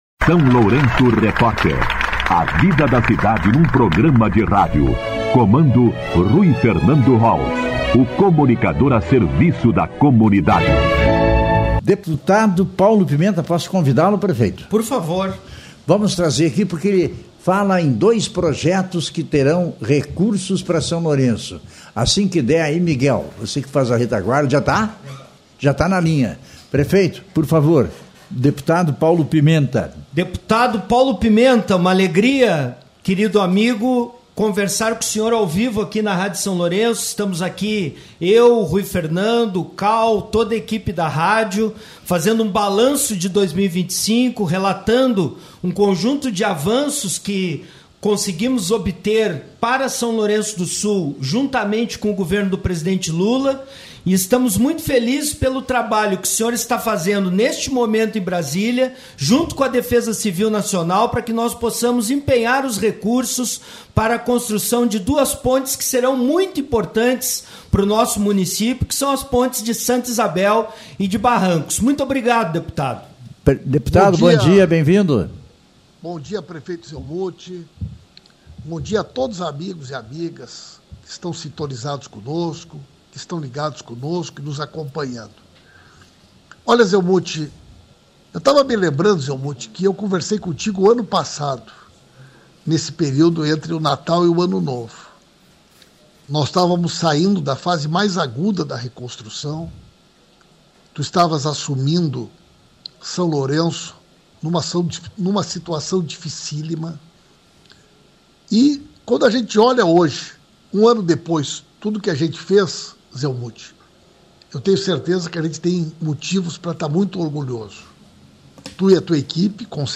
Entrevista com o prefeito municipal Zelmute Marten e o deputado federal Paulo Pimenta
Entrevista-31.12-parte-1-Prefeito-Zelmute-Marten.mp3